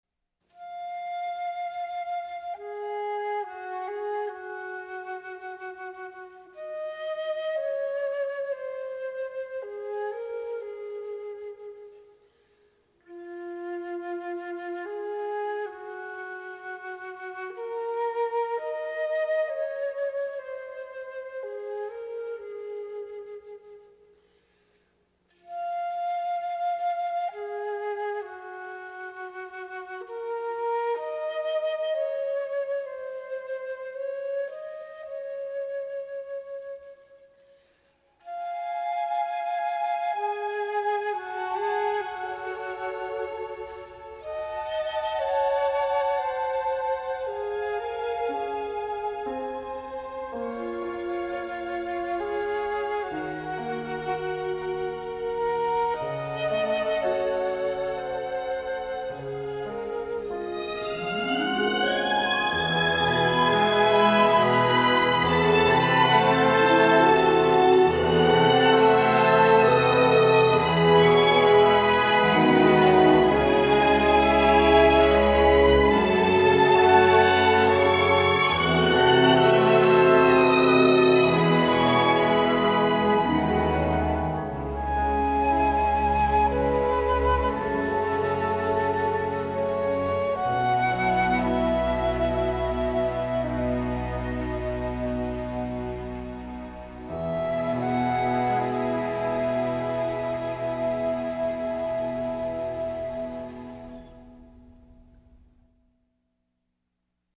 1989   Genre: Soundtrack    Artist